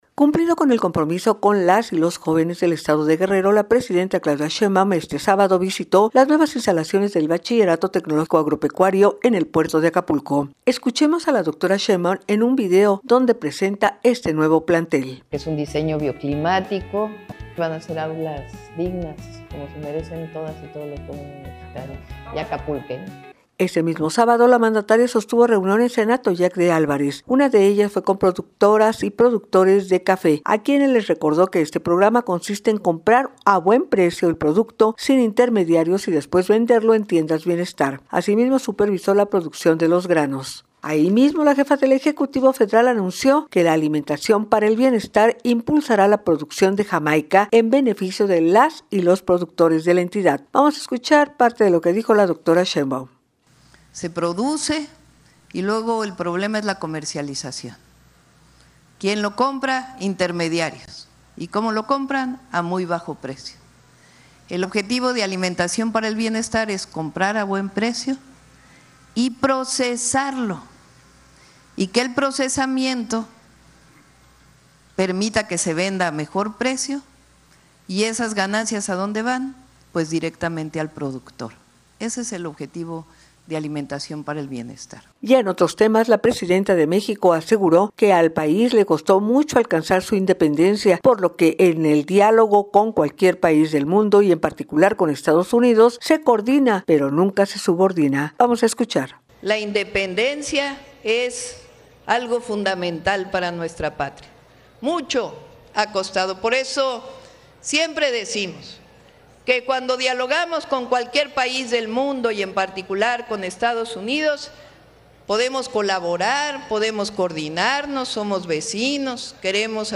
– Cumpliendo con el compromiso con las y los jóvenes del estado de Guerrero, la presidenta Claudia Sheinbaum visito las nuevas instalaciones del Bachillerato Tecnológico Agropecuario en el puerto de Acapulco. Escuchemos a la doctora Sheinbaum en un video donde presenta el nuevo plantel.